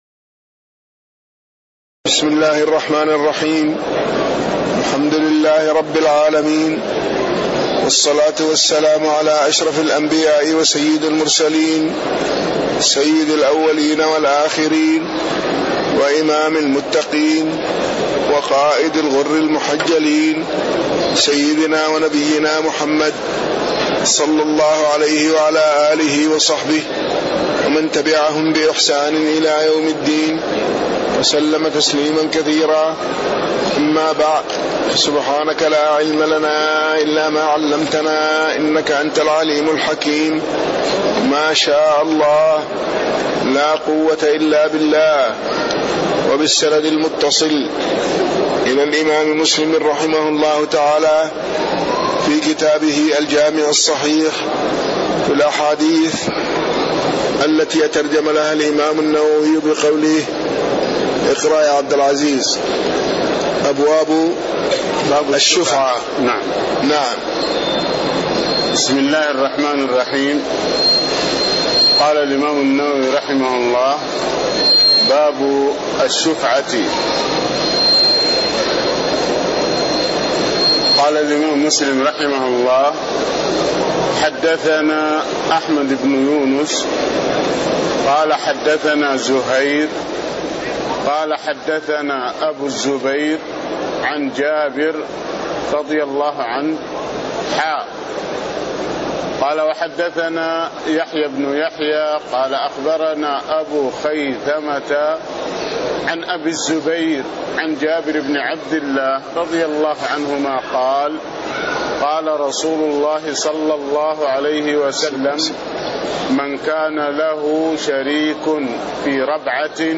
تاريخ النشر ٢٧ ربيع الأول ١٤٣٥ هـ المكان: المسجد النبوي الشيخ